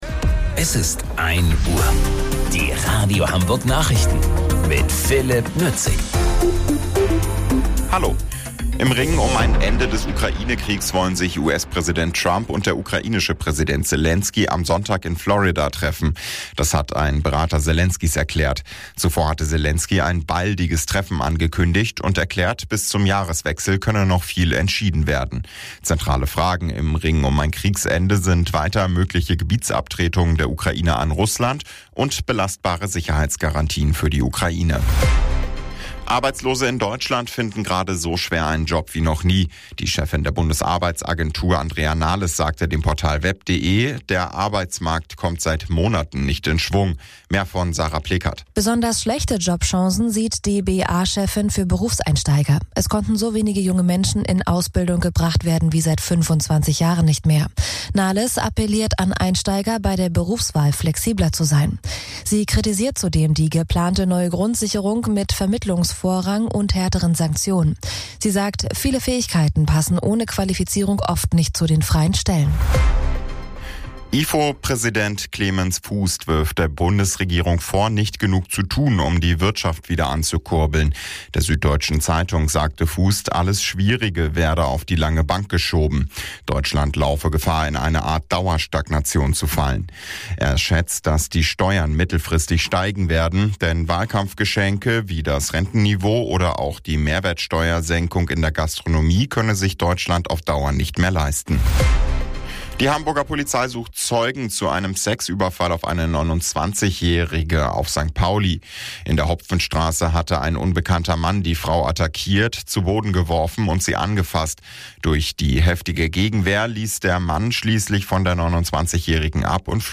Radio Hamburg Nachrichten vom 27.12.2025 um 01 Uhr